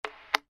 Звуки рупора